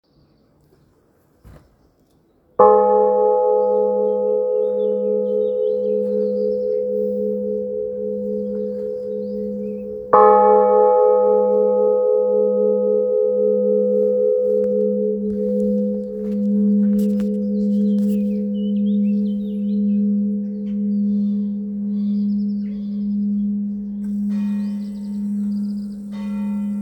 cloche - Inventaire Général du Patrimoine Culturel
Enregistrement sonore par tintement manuel (juin 2025).